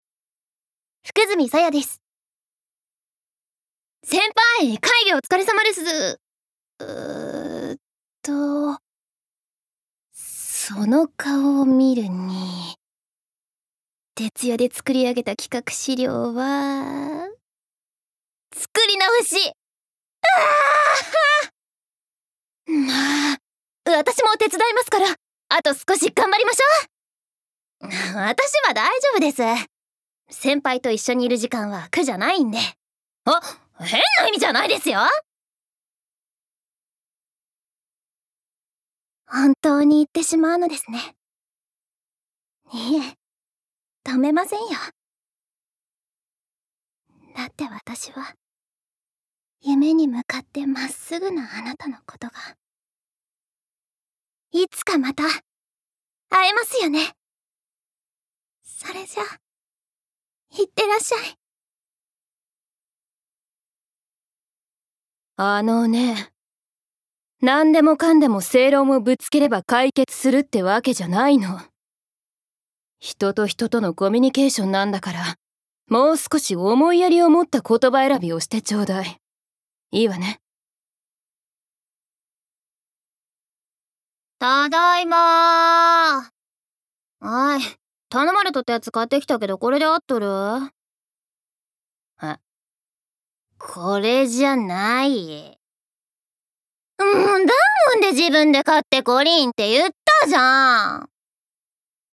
Voice Sample
ボイスサンプル